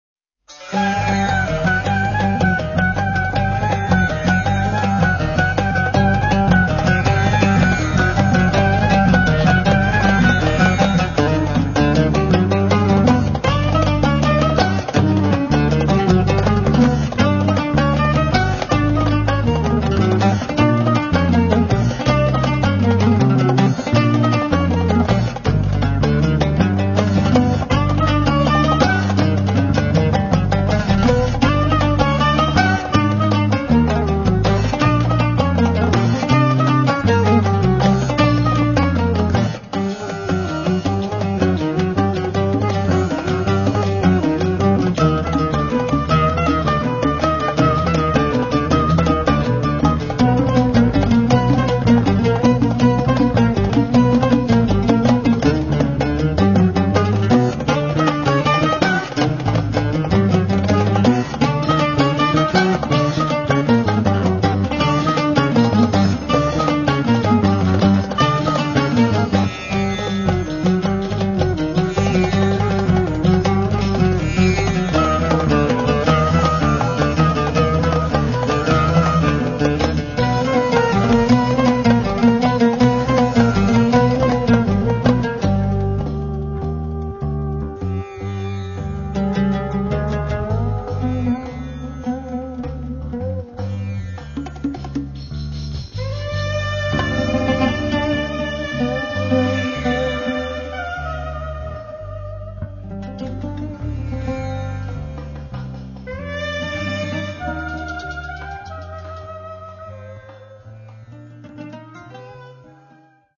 electroacoustic & electric bass, guitar
saxophones, bass clarinet, wooden flute
sitar, oud, guitars
percussions, tanpura
Registrato a Bari